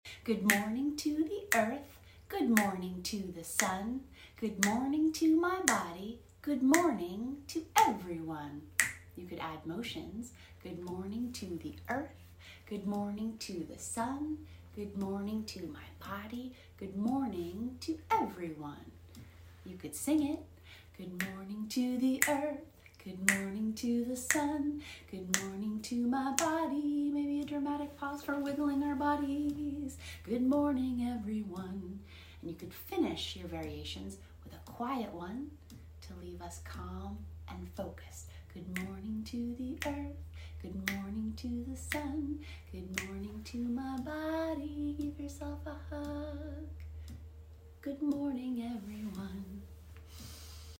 ☀ Here’s an easy-to-remember morning song for young children grounded in rhythm. The structure inherent in a consistent beat helps children organize their bodies and regulate themselves.
Follow for more mindful music for kids, and more peace and calm for you.